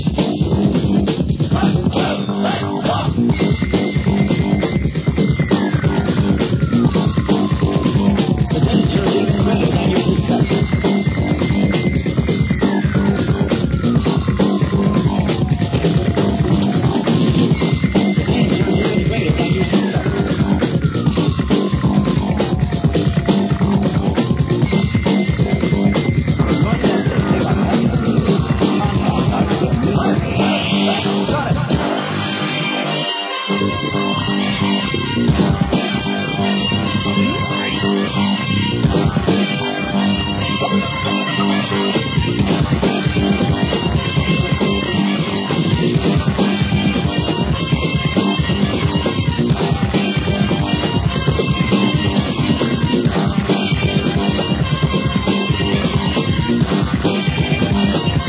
Children in Need Nite